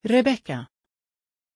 Pronunția numelui Rebecka
pronunciation-rebecka-sv.mp3